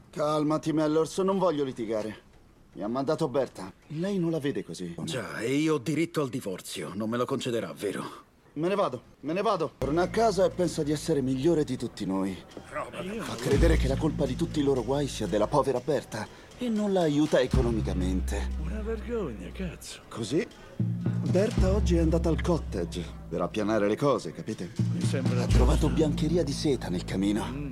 nel film "L'amante di Lady Chatterley", in cui doppia Nicholas Bishop.